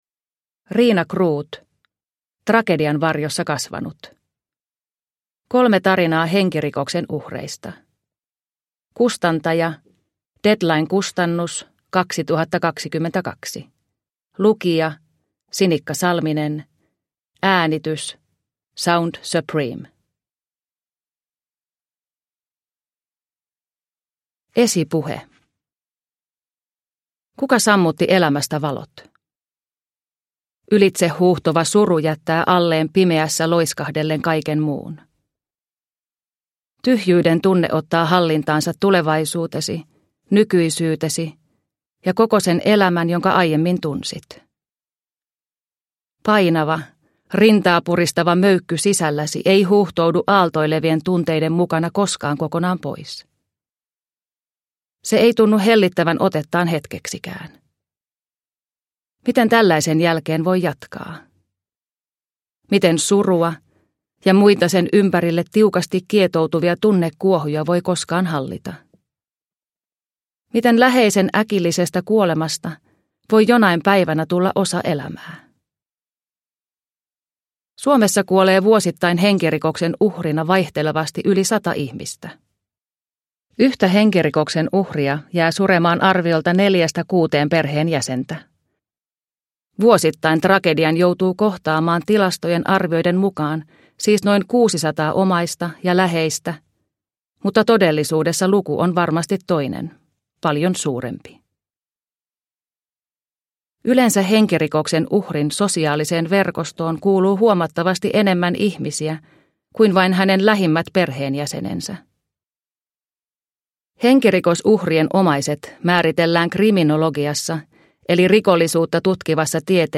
Tragedian varjossa kasvanut – Ljudbok – Laddas ner